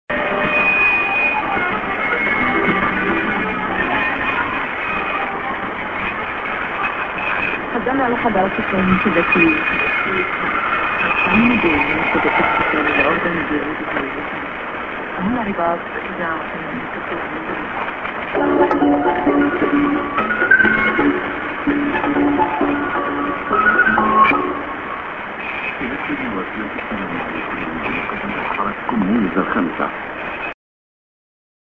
->ANN(men)->SJ->ANN(man)